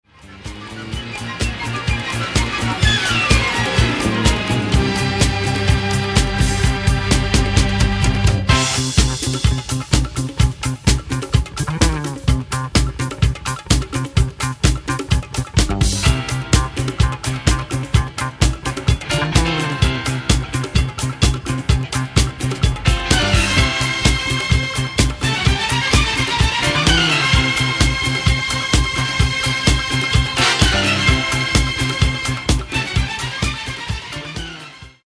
Genre:   Disco Soul